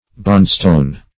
Search Result for " bondstone" : The Collaborative International Dictionary of English v.0.48: Bondstone \Bond"stone`\ (b[o^]nd"st[=o]n`), n. [Bond,n.+ stone.]